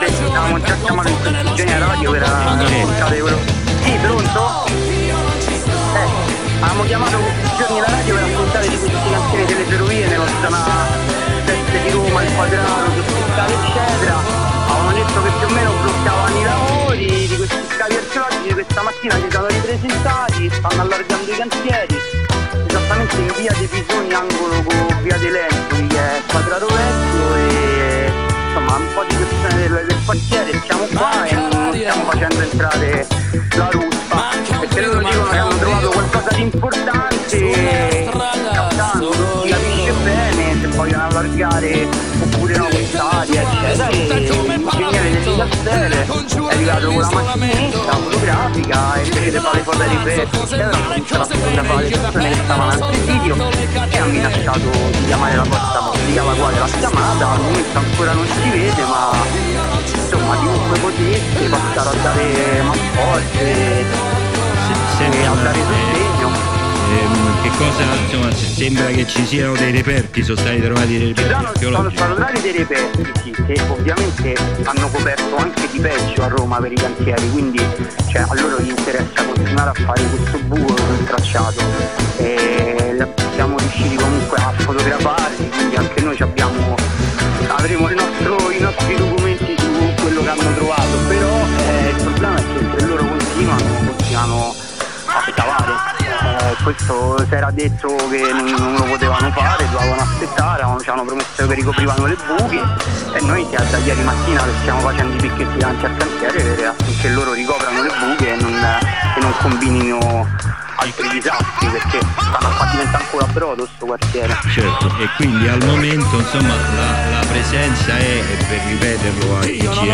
Dibattito integrale